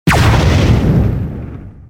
OtherHit1.wav